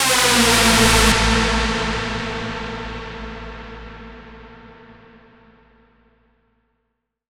Big Hoover.wav